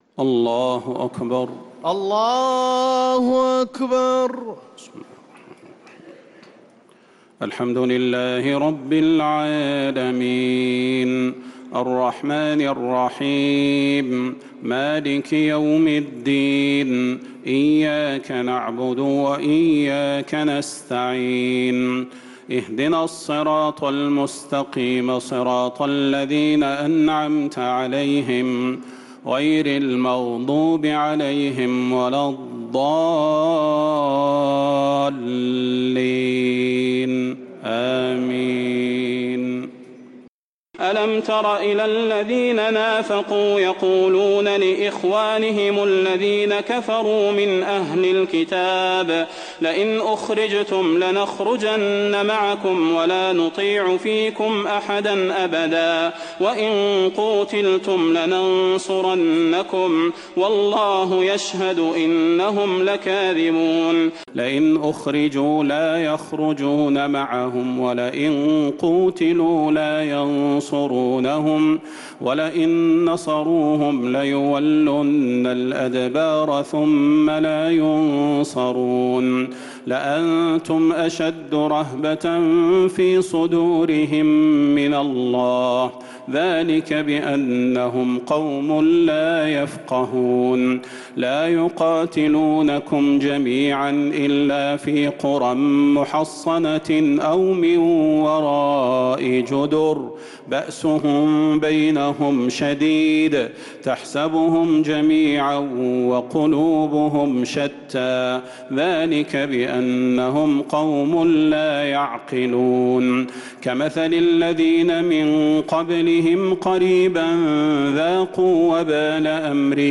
تهجد ليلة 27 رمضان 1446هـ من سورة الحشر (11-24) الى سورة الجمعة كاملة | Tahajjud 27th night Ramadan 1446H Surah Al-Hashr to Al-Jumu'a > تراويح الحرم النبوي عام 1446 🕌 > التراويح - تلاوات الحرمين